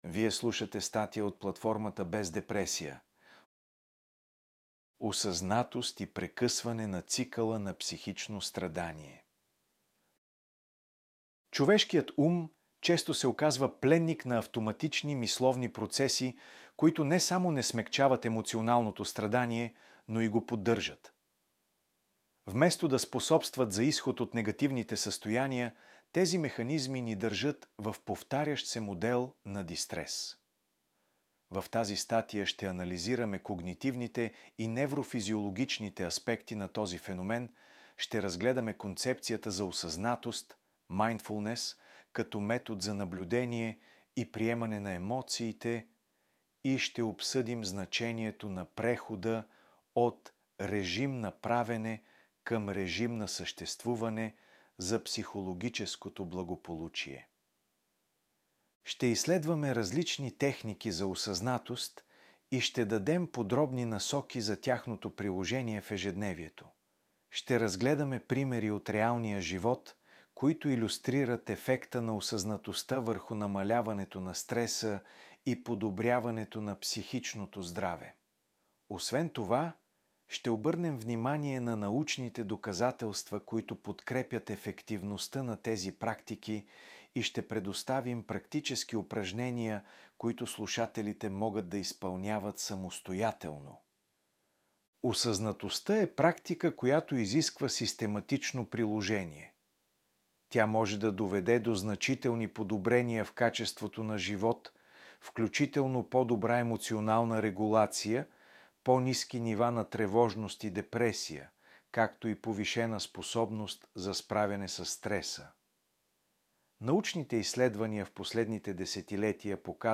::: аудио статия 1 ::: Медитация за лечебната сила на осъзнаването